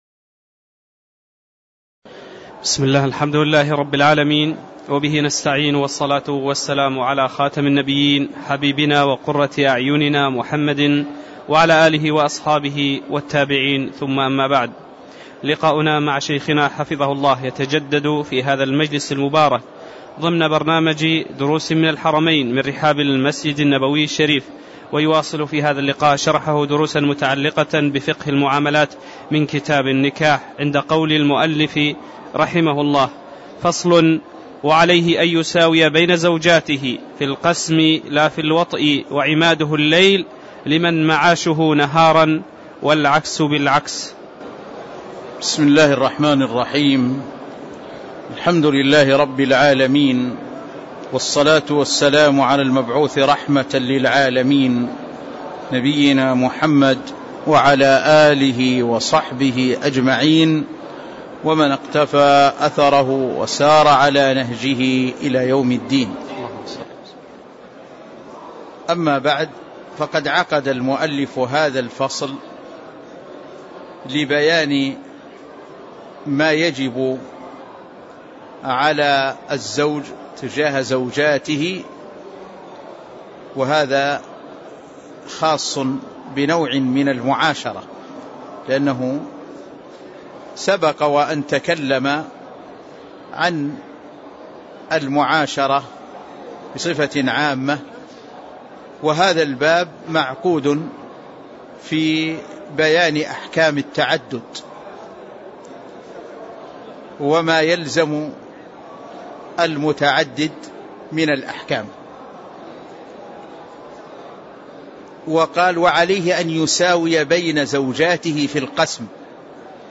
تاريخ النشر ٢٨ جمادى الأولى ١٤٣٧ هـ المكان: المسجد النبوي الشيخ